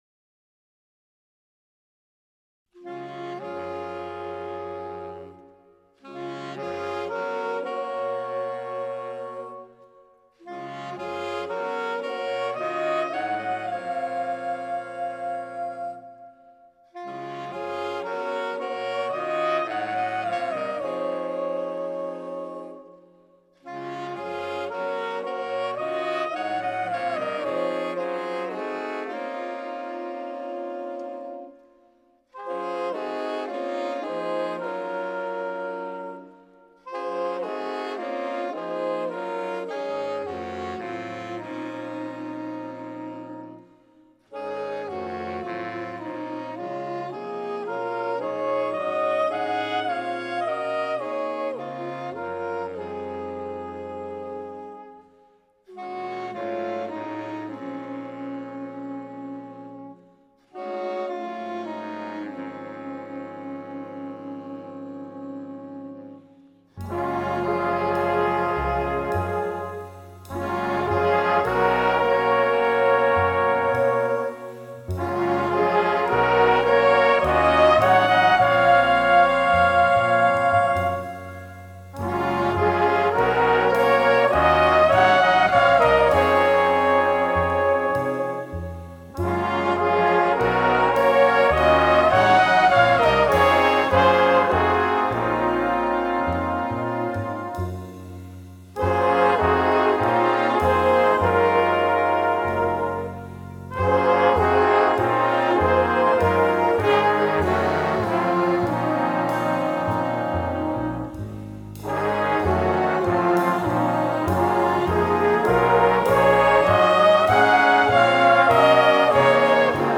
wordless voice doubling the soprano sax melody